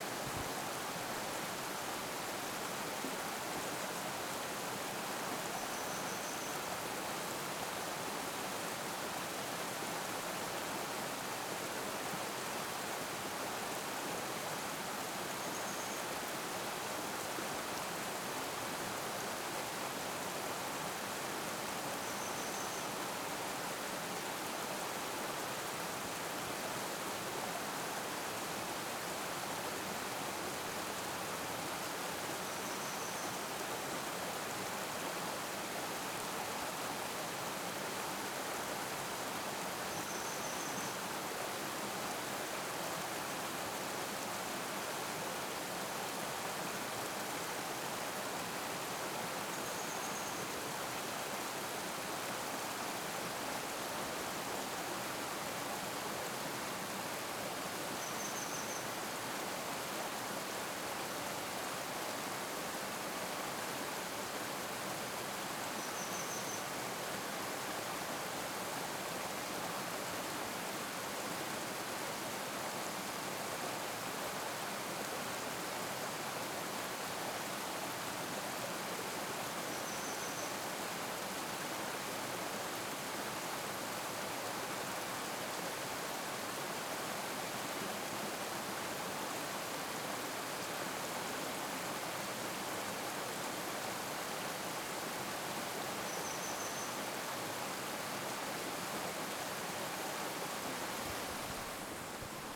【自然環境音シリーズ】鳥のさえずり せせらぎ
なのでタイトルにあるように川沿いの「滝道」で集音した鳥のさえずりとせせらぎをお届けいたします^0^
こちらの古風な橋の上で集音しました。
少しノイズが気になりますね（笑）
TASCAM(タスカム) DR-07Xのステレオオーディオレコーダー使用しています。